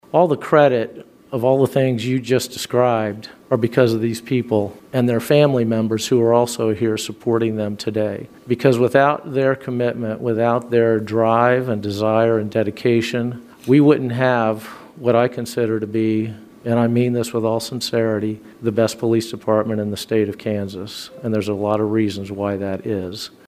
After three years and one month leading the Riley County Police Department, Riley County Police Department Director Dennis Butler sat in on his final Law Board meeting Tuesday afternoon at Manhattan City Hall.